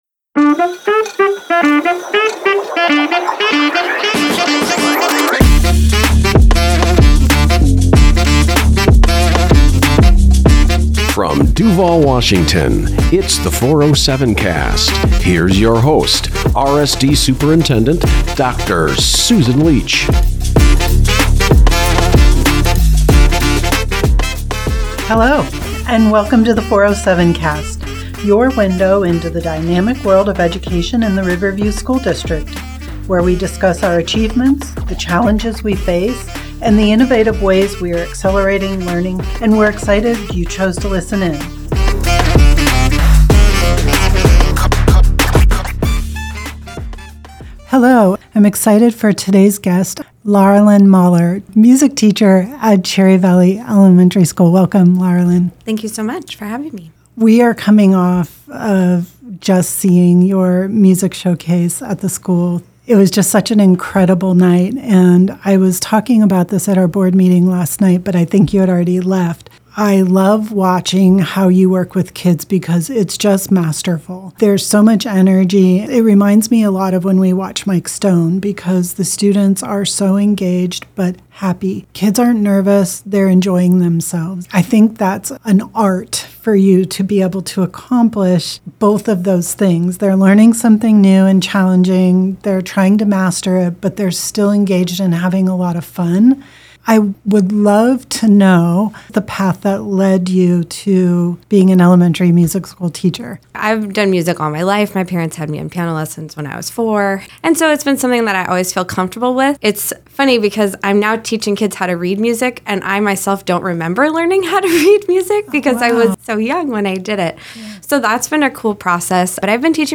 This episode includes musical performances by Riverview students!